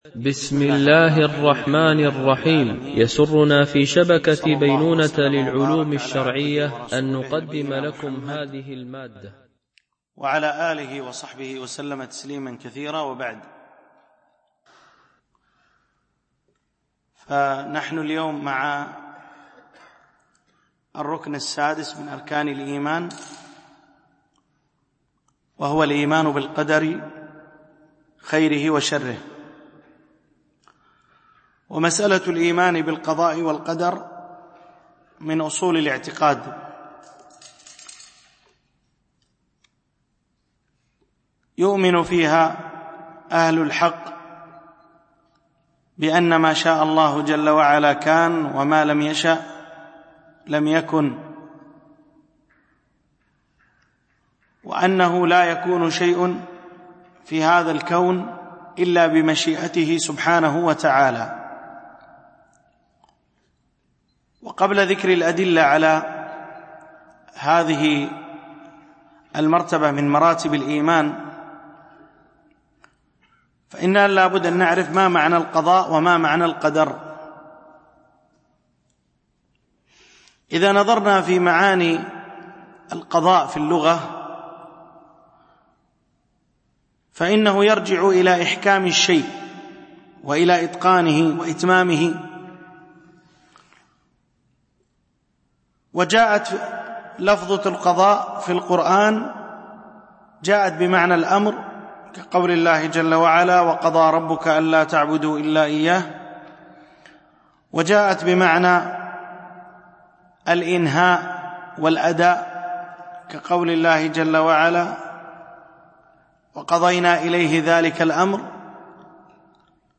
شرح حديث جبريل في بيان مراتب الدين - الدرس 16